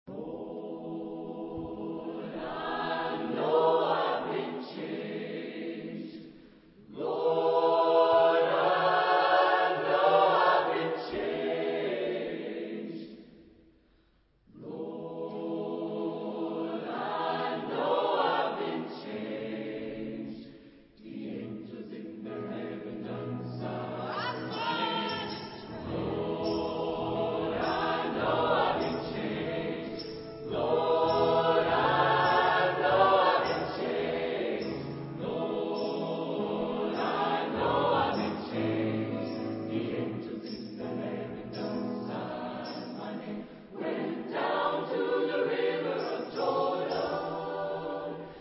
Genre-Style-Forme : Gospel ; Sacré
Caractère de la pièce : librement
Type de choeur : SATB  (4 voix mixtes )
Tonalité : mi majeur
Réf. discographique : Internationaler Kammerchor Wettbewerb Marktoberdorf